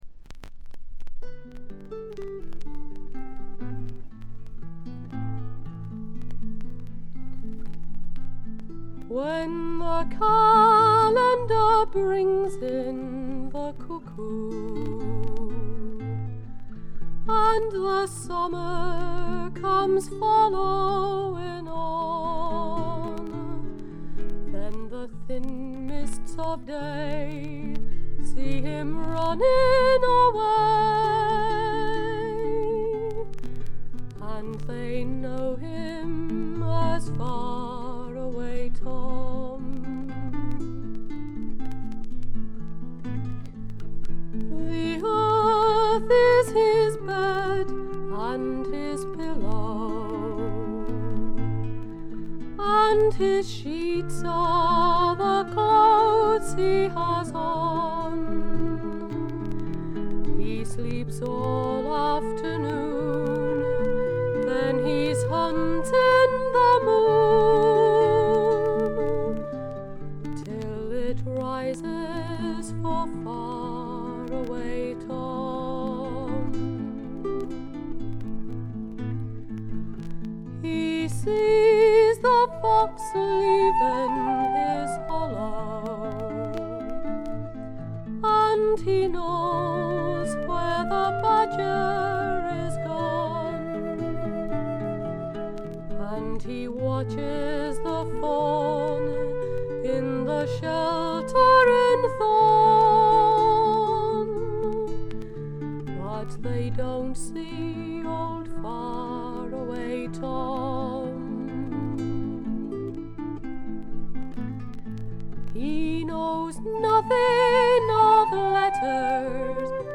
試聴曲は現品からの取り込み音源です。
Flute